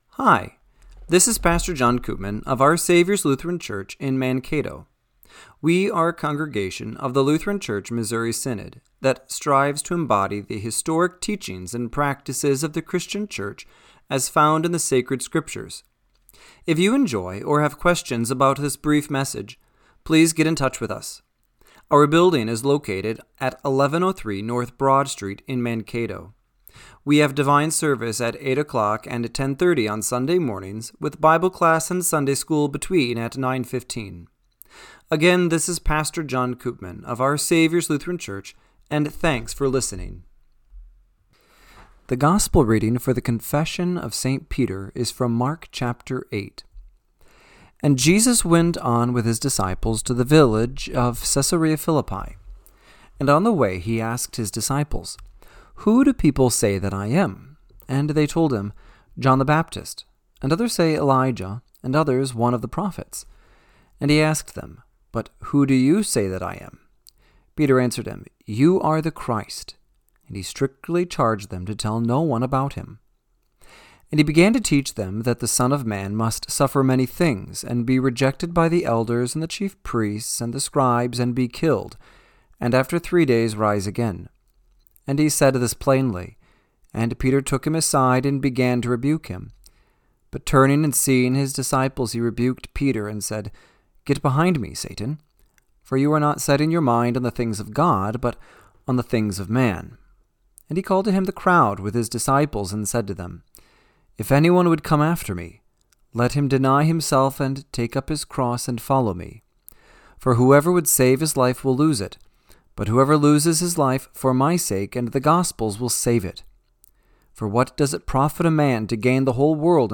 Radio-Matins-1-18-26.mp3